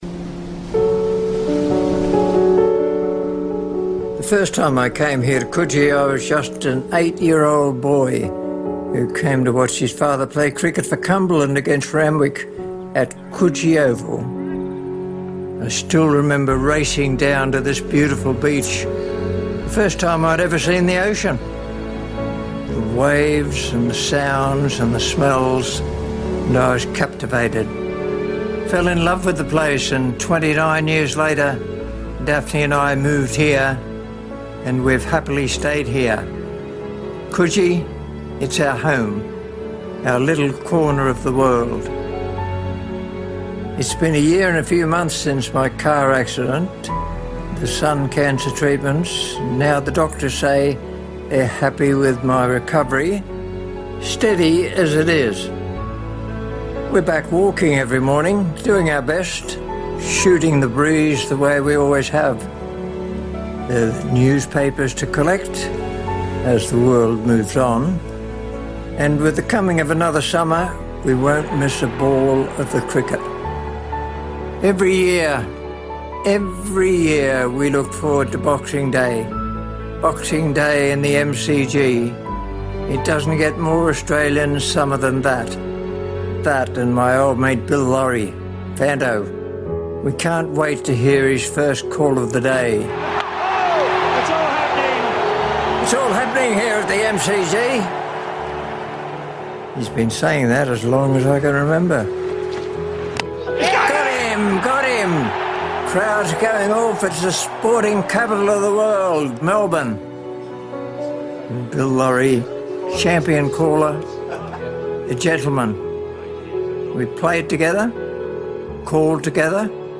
A touching tribute from Bill Lawry prior to the Boxing Day Test last year